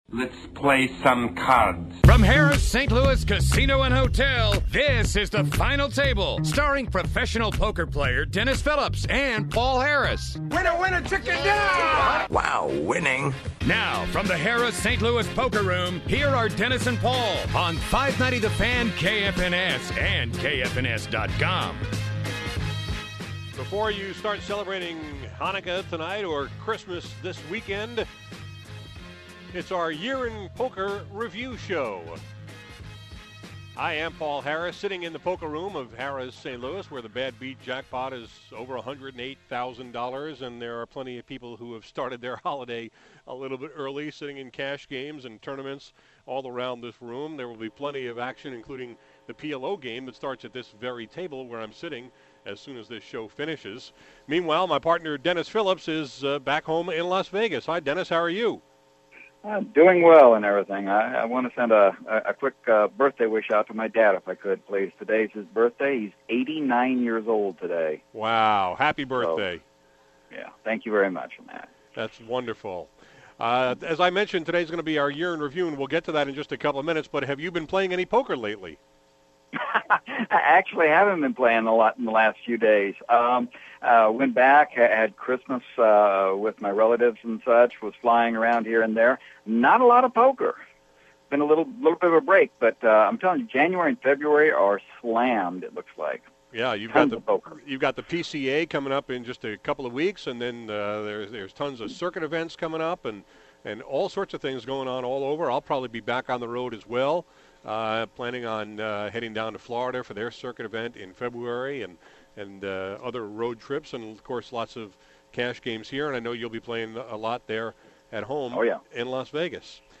Today on the Final Table radio show, we recapped the biggest poker stories of 2011, with help from two of the industry’s top reporters